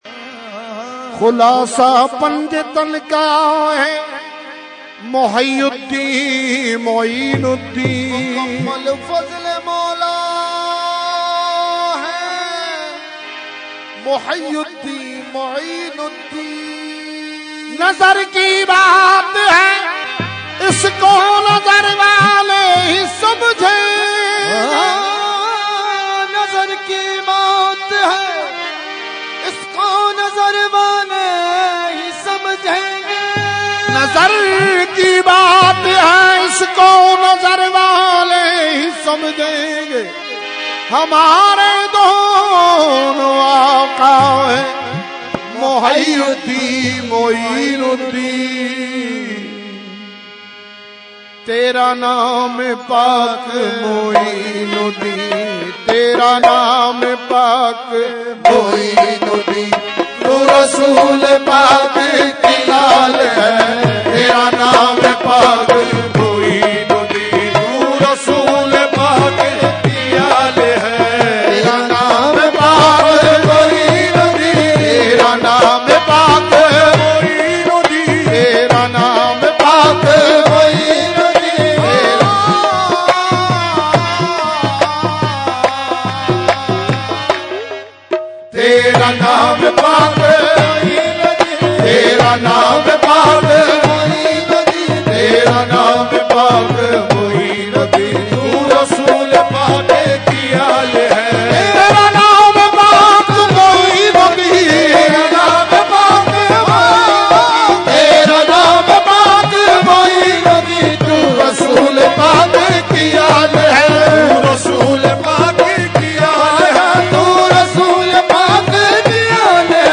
Category : Qawali | Language : UrduEvent : Urs Qutbe Rabbani 2014